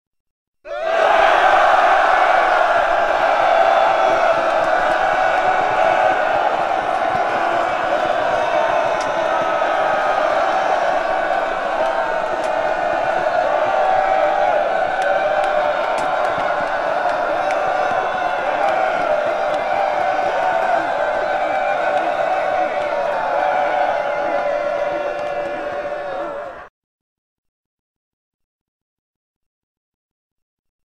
Tiếng Hét xung trận chiến đấu
Thể loại: Đánh nhau, vũ khí
Description: Hiệu ứng âm thanh tiếng nhiều người, nhóm bin lính tiếng hét xung trận chiến đấu, xông lên chiến đấu...
tieng-het-xung-tran-chien-dau-www_tiengdong_com.mp3